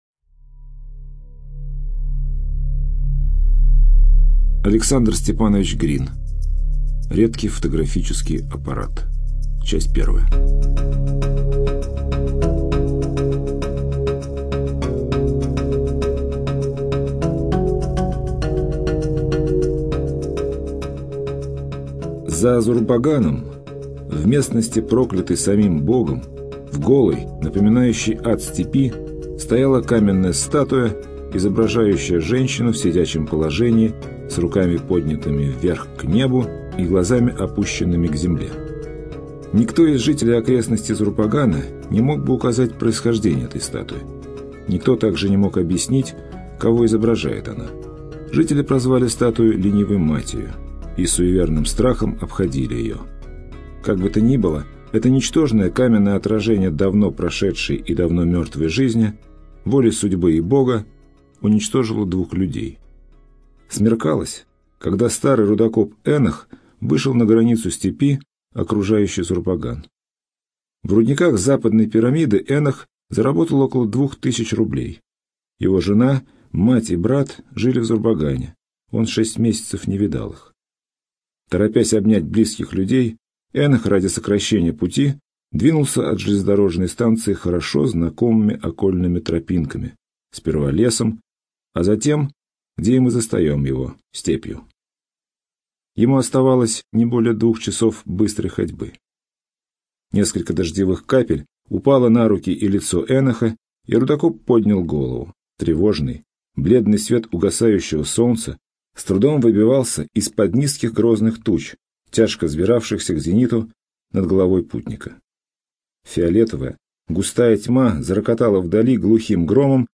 ЖанрКлассическая проза